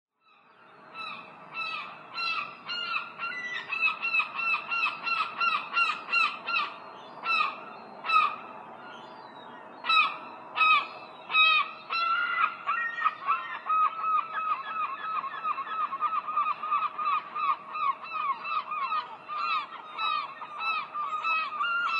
Звуки чайки
Крик чайки в полете